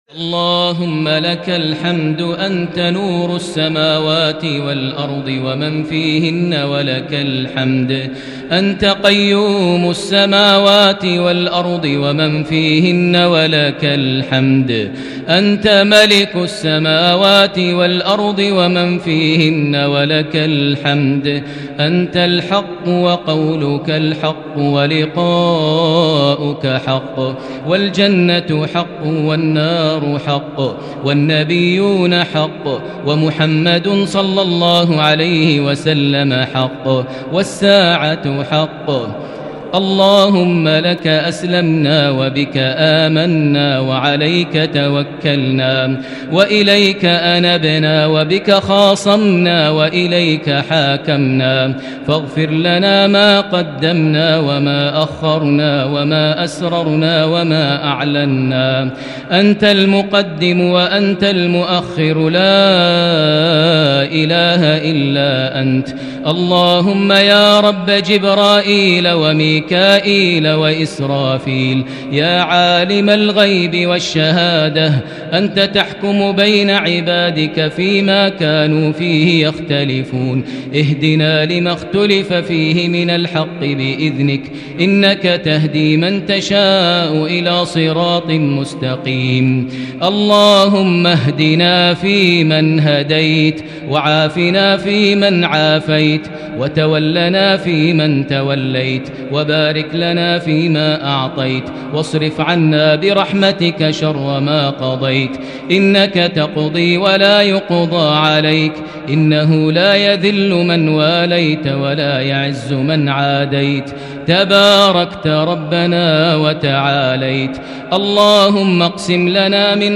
دعاء ليلة 5 رمضان 1441هـ > تراويح الحرم المكي عام 1441 🕋 > التراويح - تلاوات الحرمين